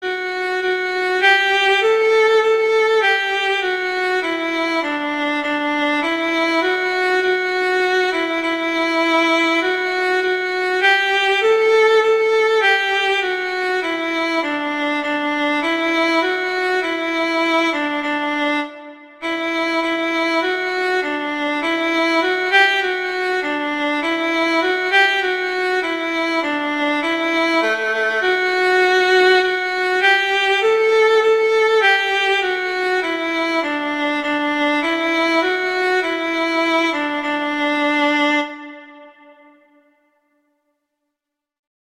arrangements for violin solo
classical, children